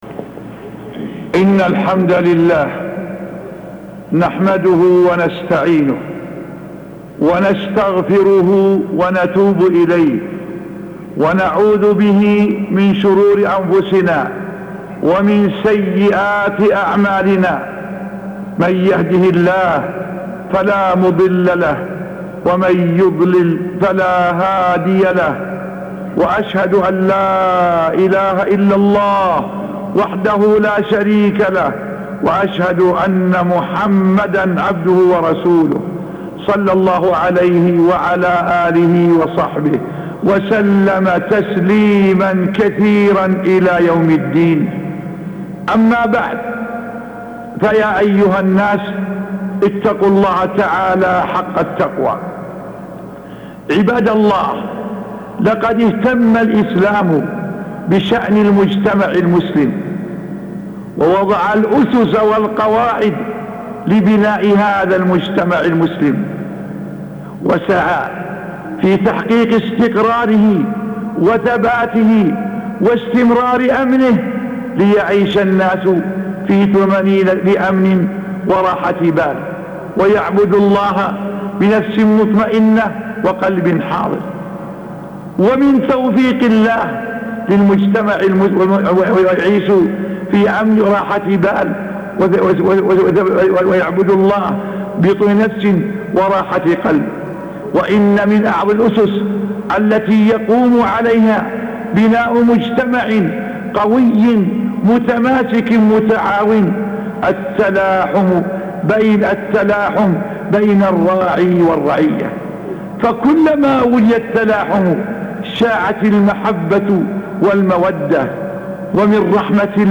خطبة من أسس بناء المجتمع التلاحم بين الراعي والرعية الشيخ عبد العزيز بن عبد الله آل الشيخ